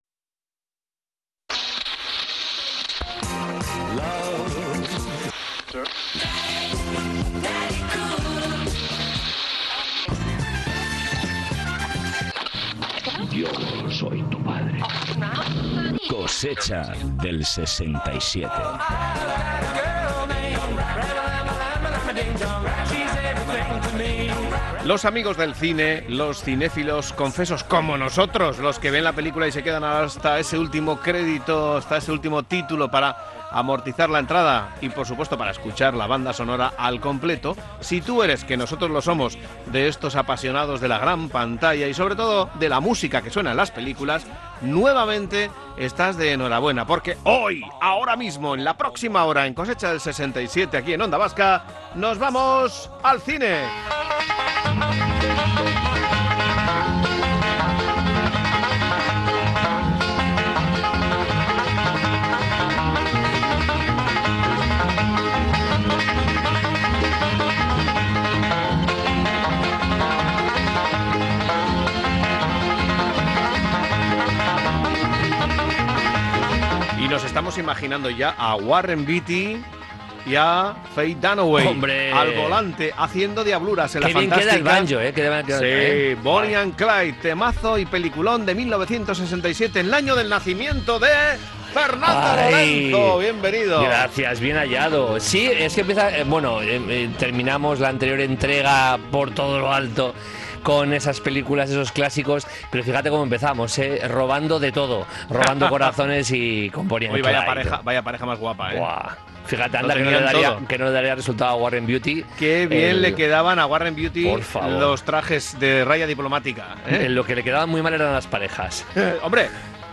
Espacio conectado a la nostalgia a través del humor y la música.
Recuerdos sonoros de otro tiempo con la música y el humor como cómplices.